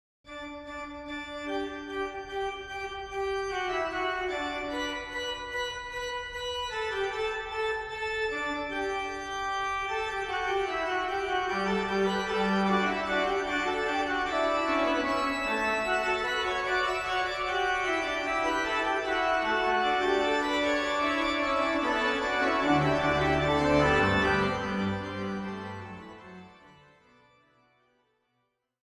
Description:   This is a collection of organ transcription.